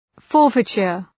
Shkrimi fonetik {‘fɔ:rfıtʃər}